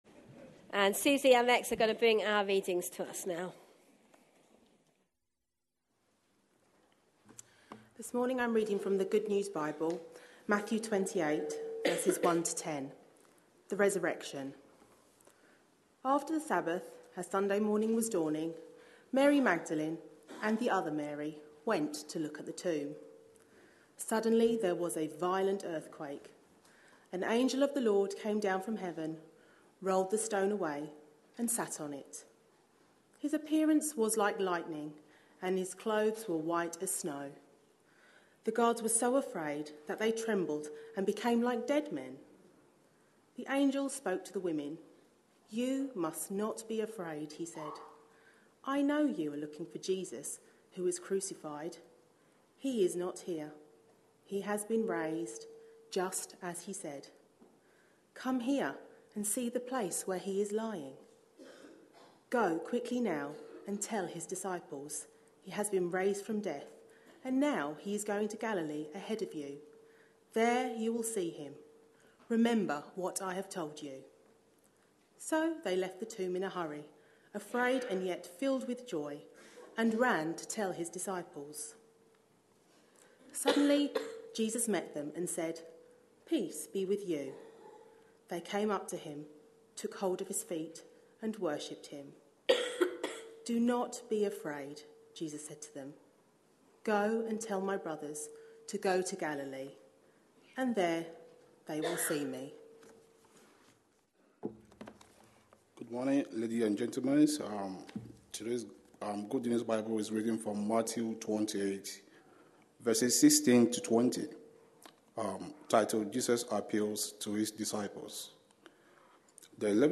A sermon preached on 27th March, 2016.